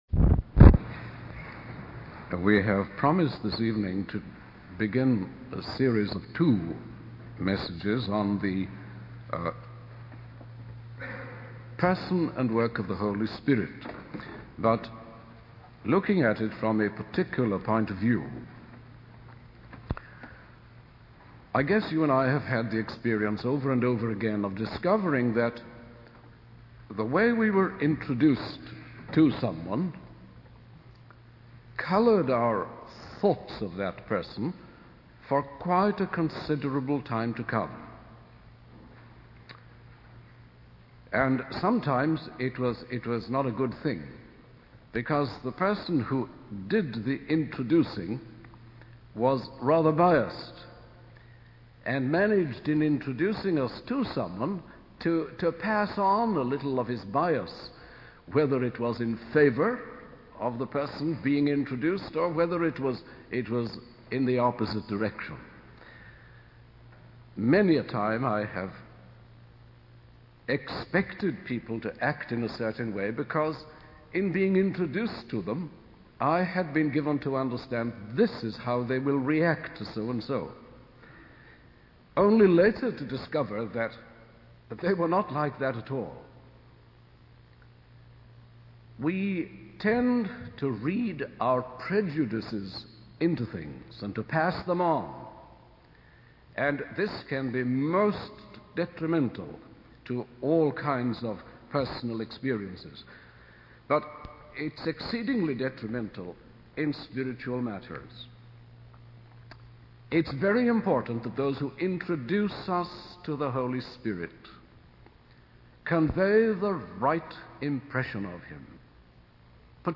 In this sermon, the speaker begins by discussing how our initial introduction to someone can shape our perception of them. He then transitions to the topic of the Holy Spirit and emphasizes the importance of being filled with the Spirit. The speaker uses the analogy of irrigation to illustrate how the Spirit's influence can transform and bring life to barren areas.